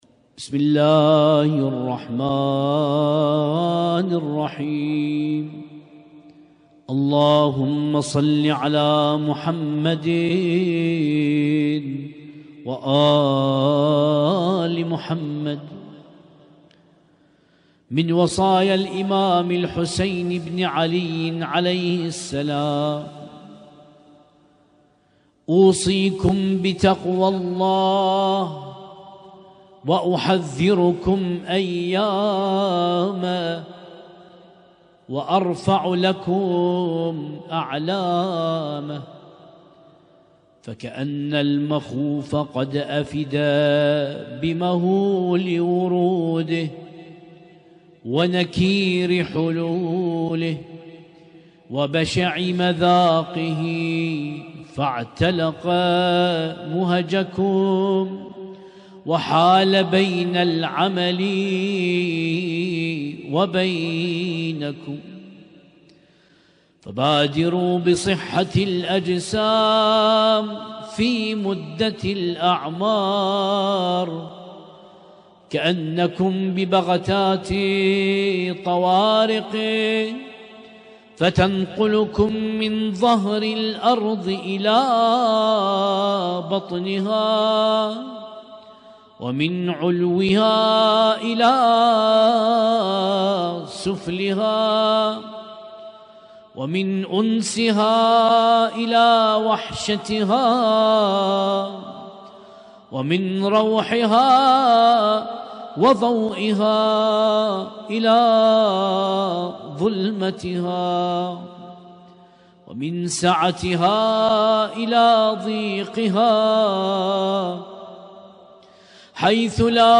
القارئ
ليلة 3 من شهر محرم الحرام 1447